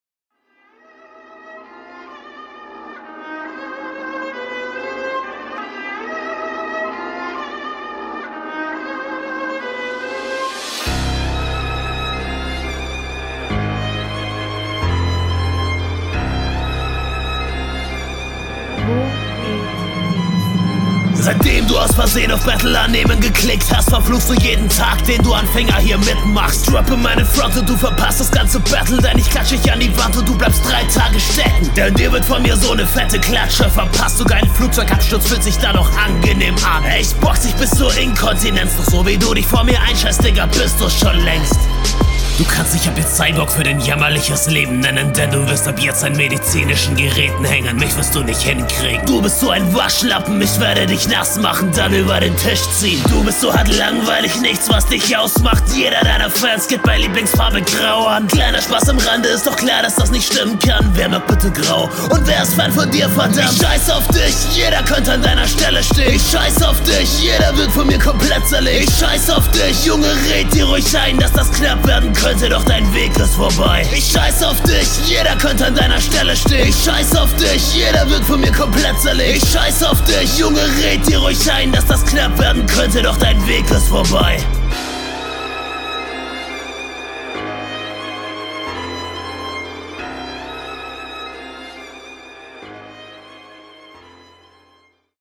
Flow: Ziemlich solider Flow.
Geiler Beat, schönes Sample. Stimme finde ich gewöhnungsbedürftig, der Flow wirkt aber durchgehend sehr safe.
Der Stimmeinsatz ist sehr speziell, mich stört er eher, aber er ist passend.